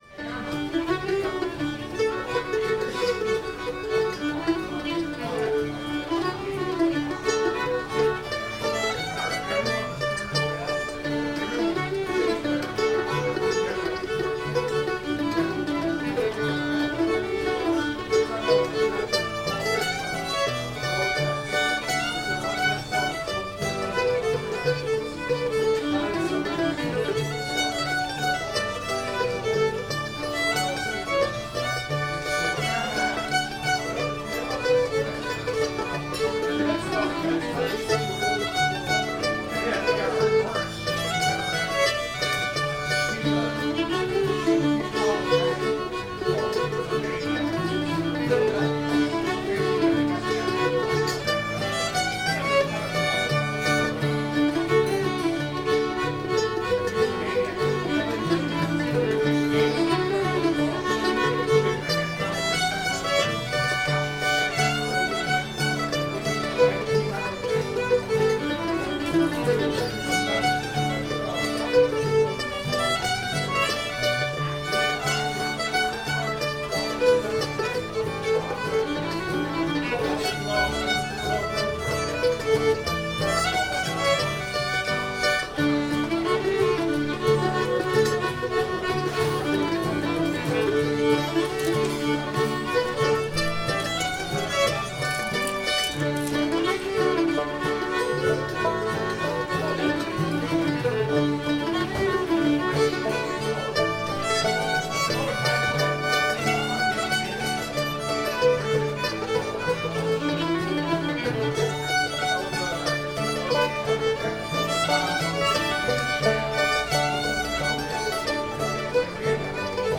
sweets o'weaver [D]